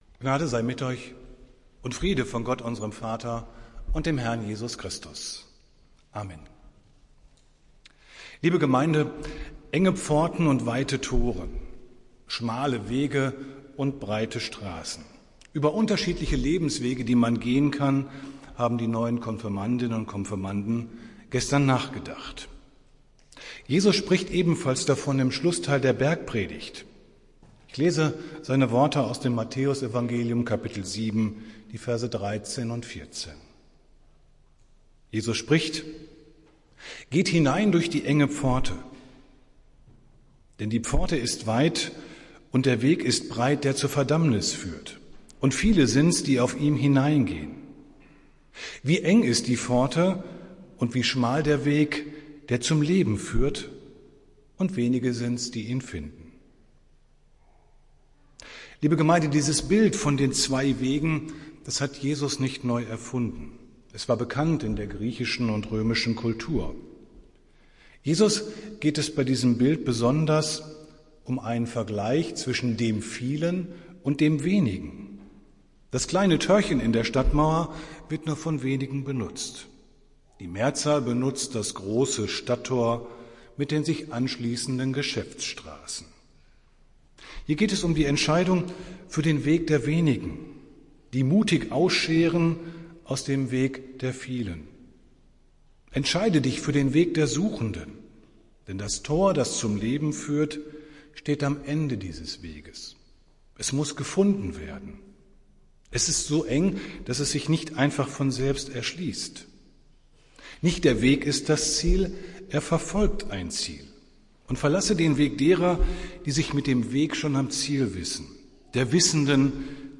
Predigt des Gottesdienstes aus der Zionskirche am Sonntag, den 21.08.2022